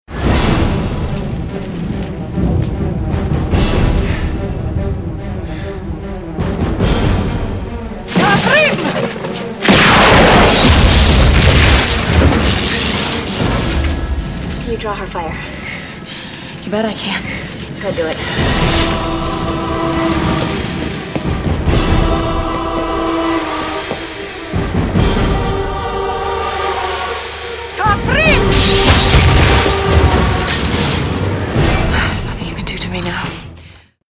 96kbps, including much dialogue and sfx.